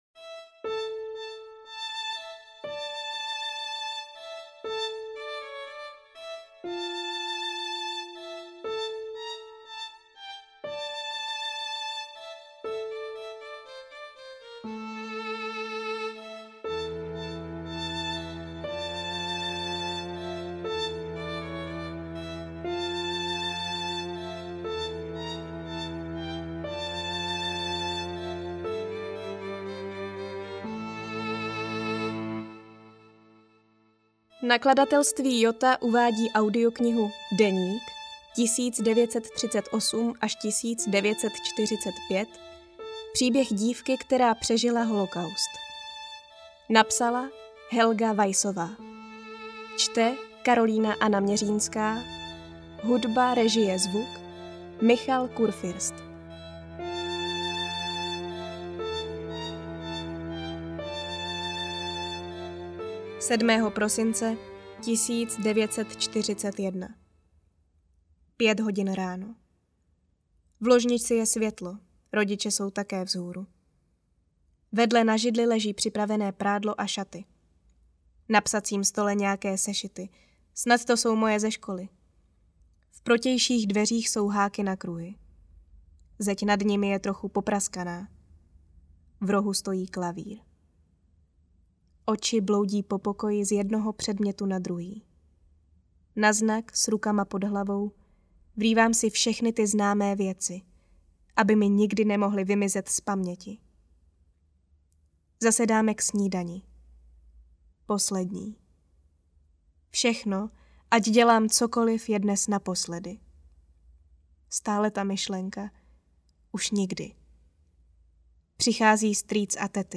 Deník 1938–1945 audiokniha
Ukázka z knihy